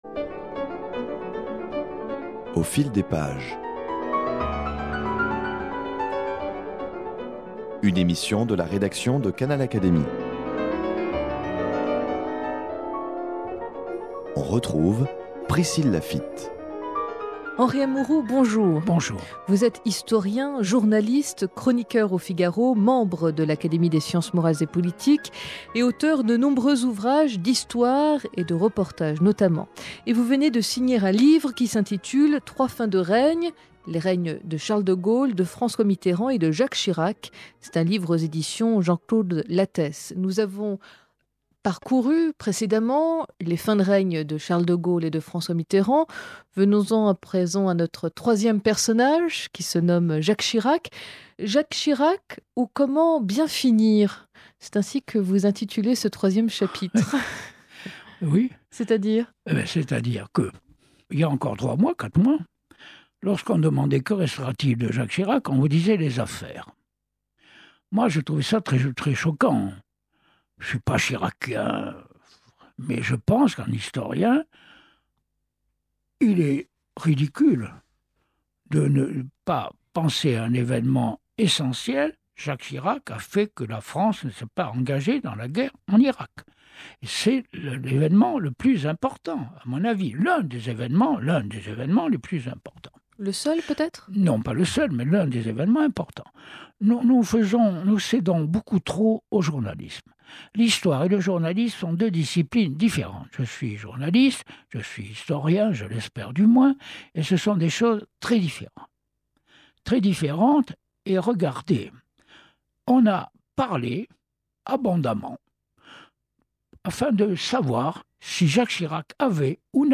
Henri Amouroux a établi les histoires parallèles de ces "trois fins de règne". Dans cette quatrième et dernière interview sur ce thème, Henri Amouroux retrace les derniers moments de Jacques Chirac à l’Elysée.